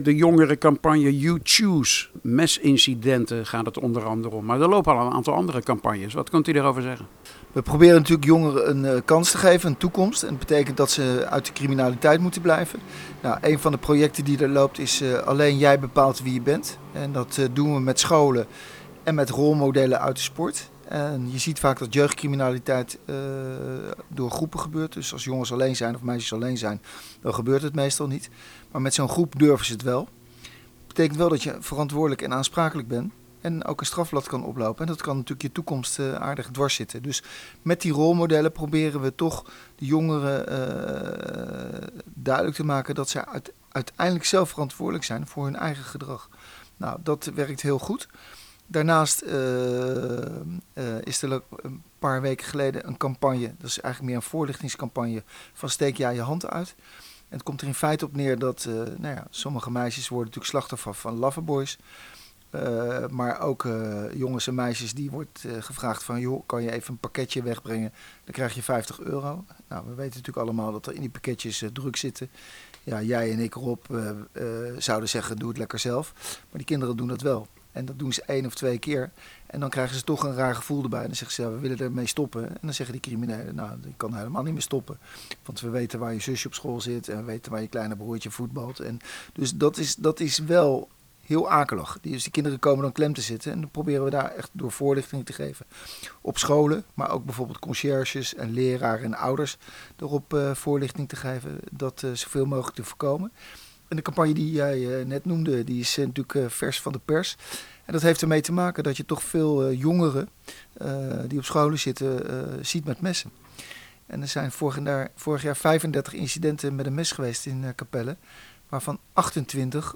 sprak met burgemeester Peter Oskam over deze projecten.